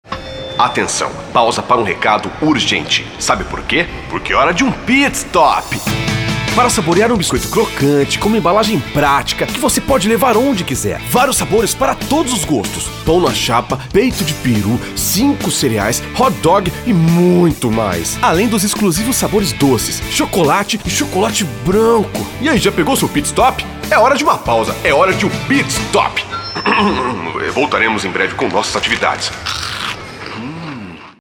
Masculino
Voz Padrão - Grave 03:08